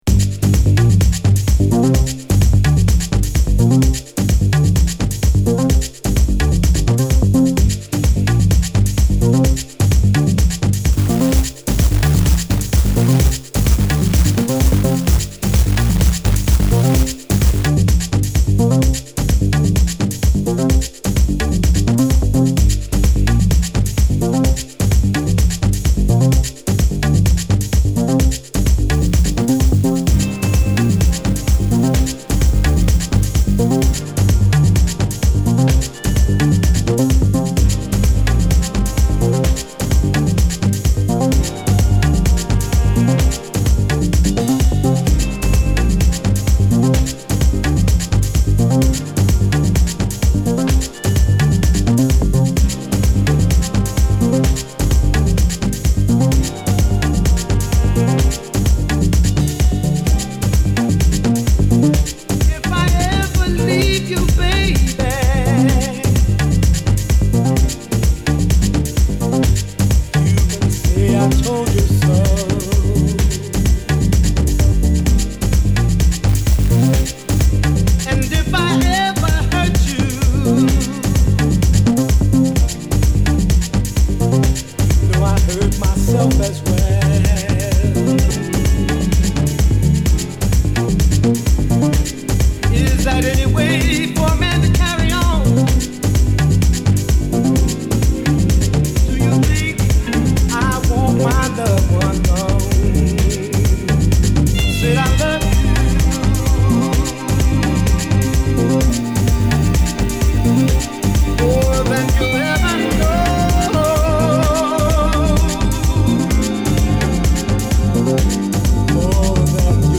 孤高のソウルシンガー